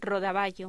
Locución: Rodaballo
Sonidos: Voz humana